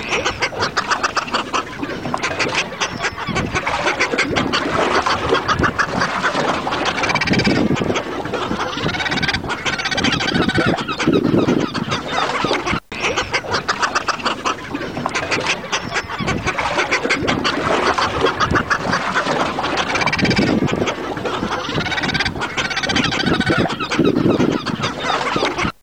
petreldamero.wav